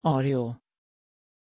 Pronunciation of Ario
pronunciation-ario-sv.mp3